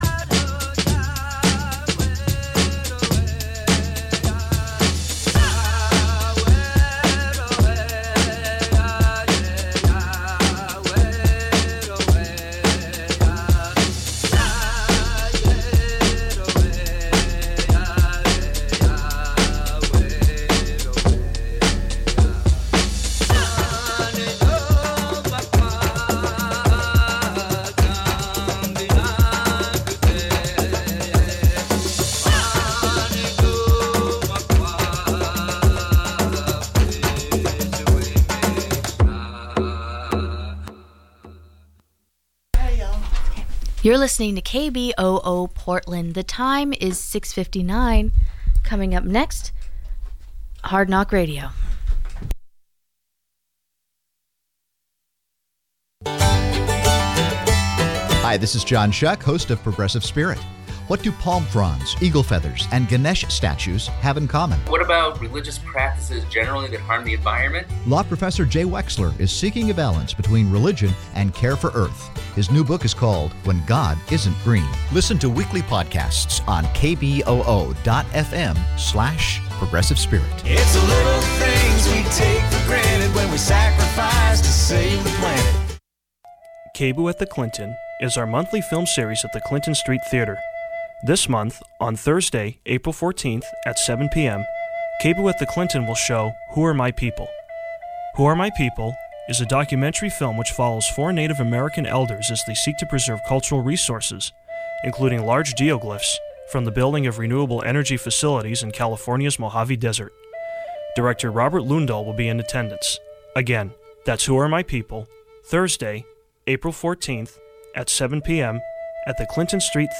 On this KBOO pledge drive episode of ETTS we will hear discussion